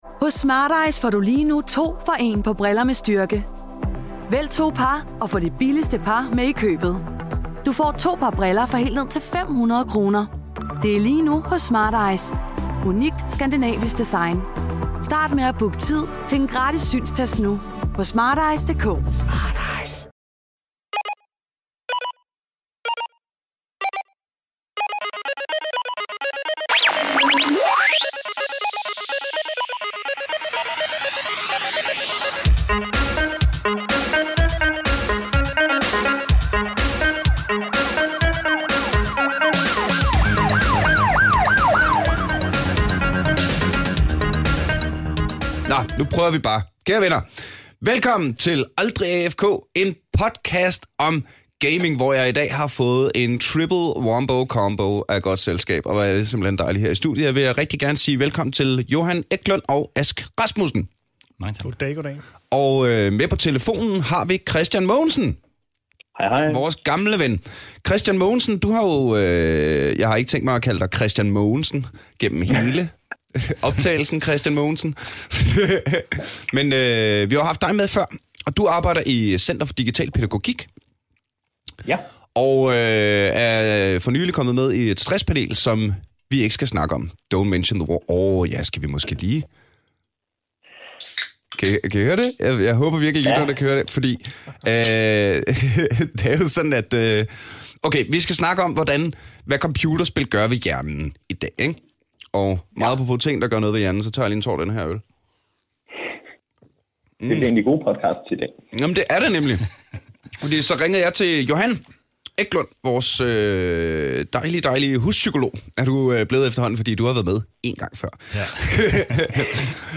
For det er kloge mænd der ved ting, som der snakker.